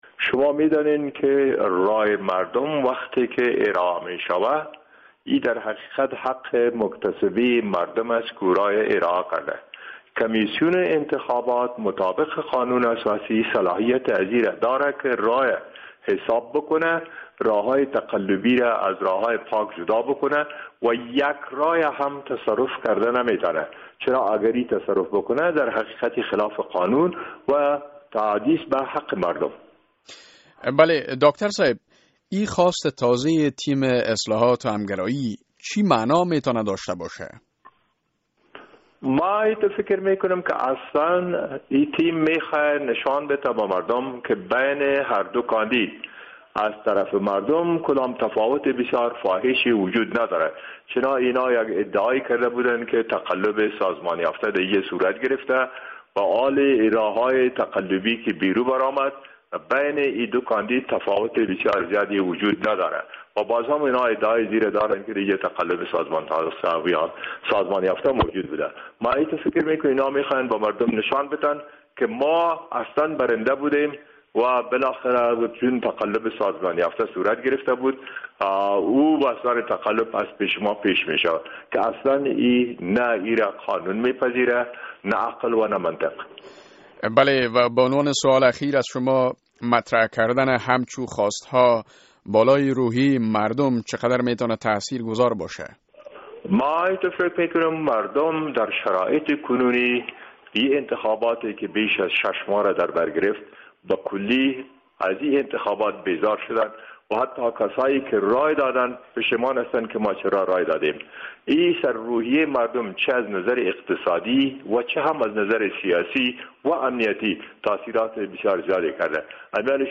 مصاحبه در مورد موضع گیری اخیر تیم اصلاحات و همگرایی